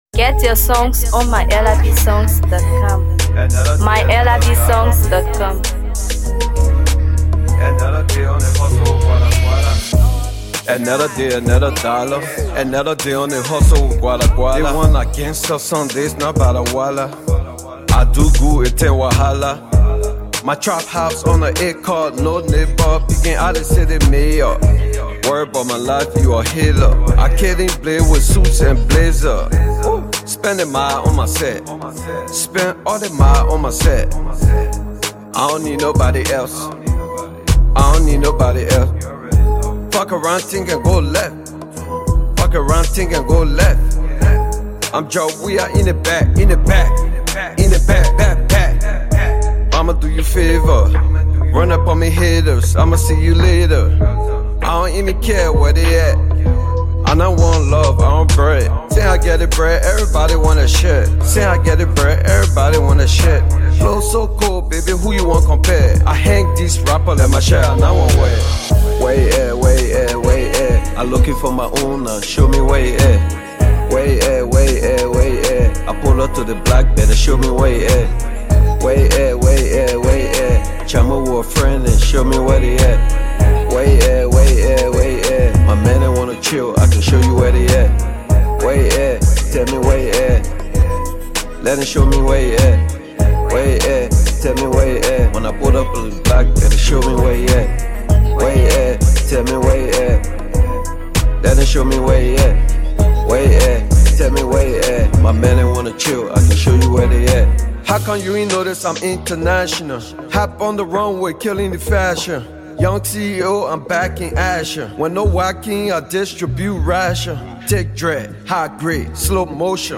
Hip HopMusic
Liberian hip-hop artist
blend contemporary beats with authentic storytelling